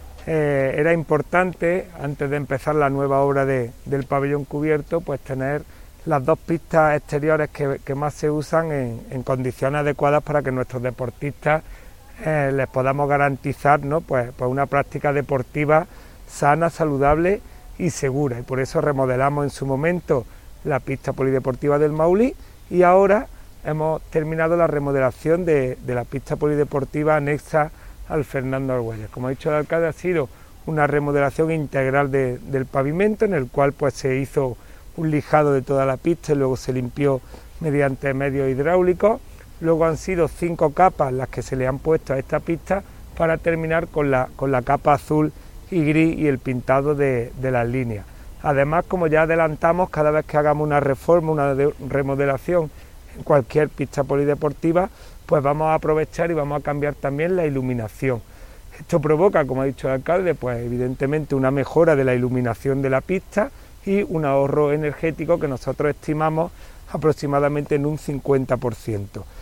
El alcalde de Antequera, Manolo Barón, y el teniente de alcalde delegado de Deportes, Juan Rosas, han informado en rueda de prensa sobre la conclusión de los trabajos desarrollados para la mejora integral de la pista polideportiva de Parquesol, anexa al pabellón Fernando Argüelles y a los campos de fútbol 7.
Cortes de voz